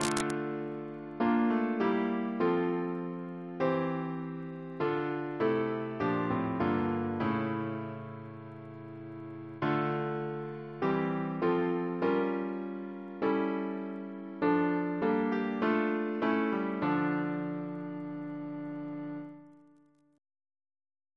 Double chant in D minor Composer: Chris Biemesderfer (b.1958)